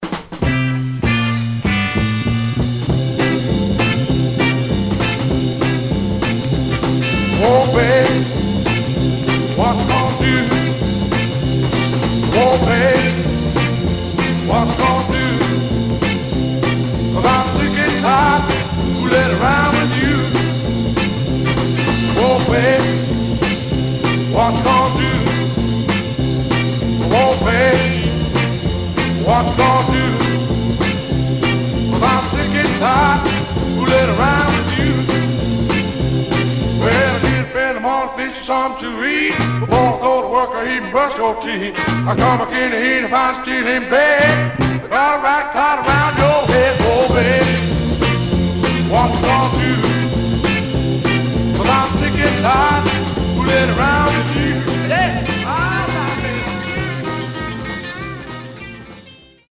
Stereo/mono Mono
Garage, 60's Punk